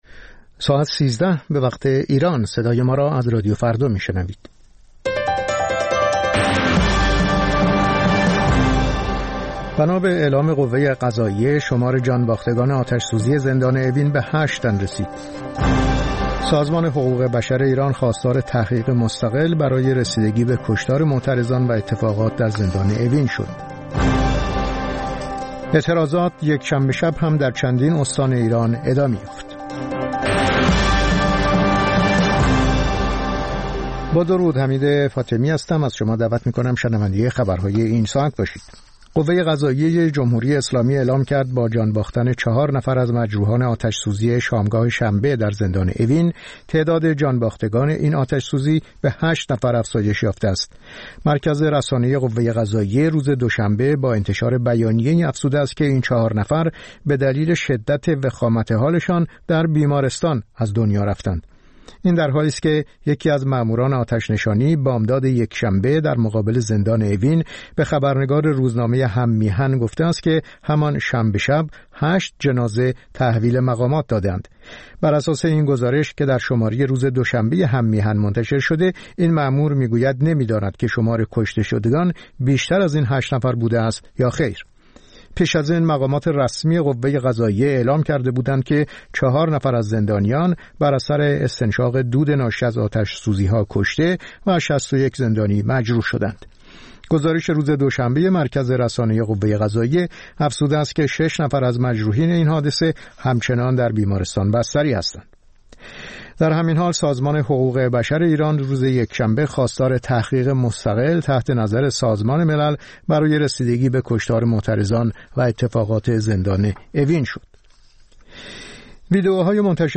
خبرها و گزارش‌ها ۱۳:۰۰